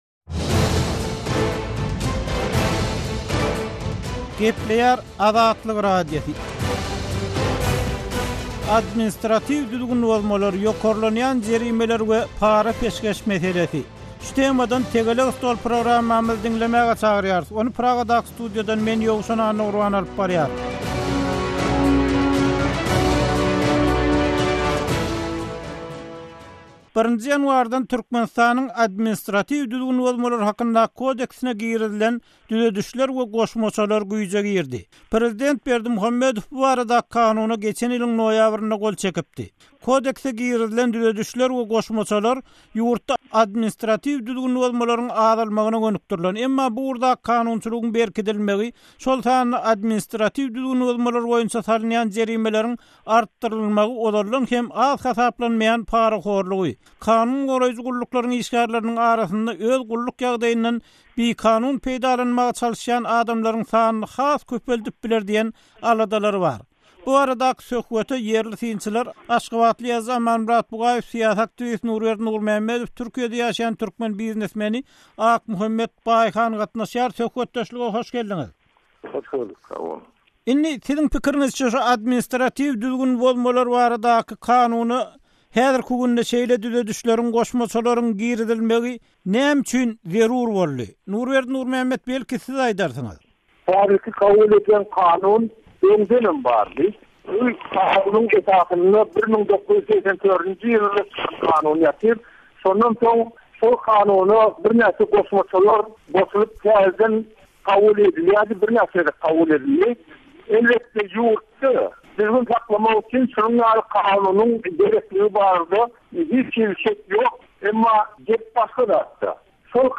Tegelek stol: Kanunlar we karamlyklar